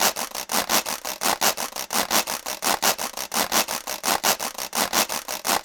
Washboard 02.wav